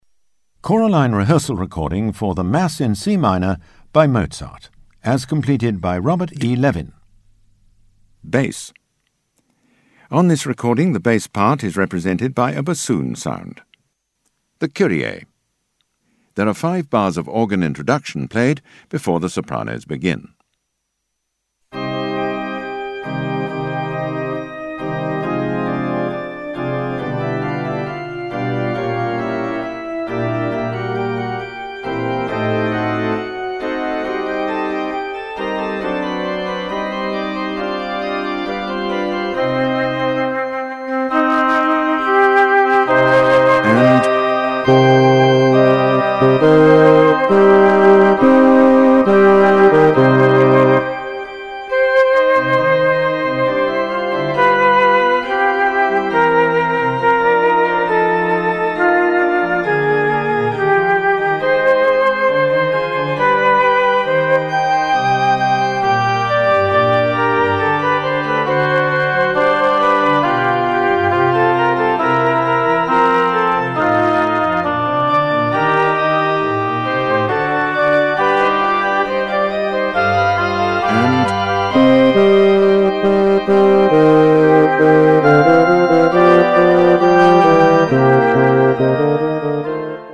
BASS
* High Sound Quality - produced by BBC Sound Engineer
* Easy To Use - a narrator calls out when to sing
* Don't Get Lost - a narrator calls out bar numbers
* Be Pitch Perfect - clearly hear the notes for your part